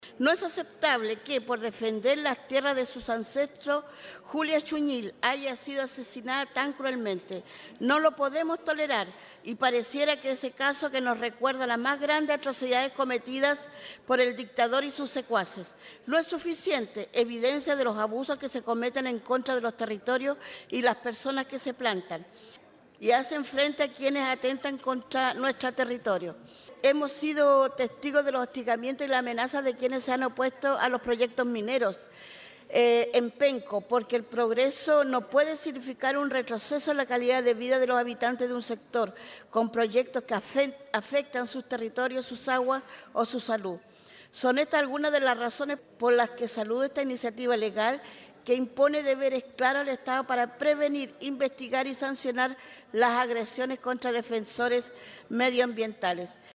También representando a la región del Biobío, la diputada del Distrito 20 María Candelaria Acevedo, del Partido Comunista, manifestó que “no podemos tolerar” lo que ocurre con “las personas que se plantan y hacen frente a quienes atentan contra el territorio”.